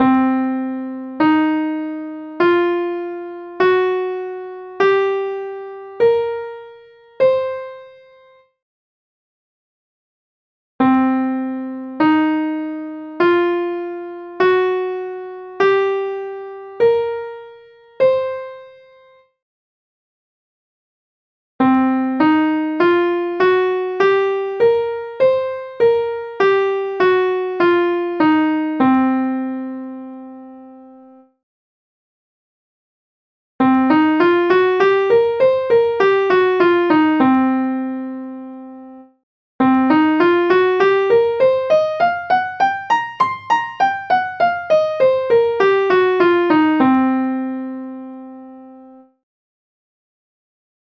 והנה אותו סולם בתוספת התבלין – הבלו נוטס, שוב, פעמיים לאט ואז מהר:
שומעים את הסאונד הבלוזי הזה?
scale-blues.mp3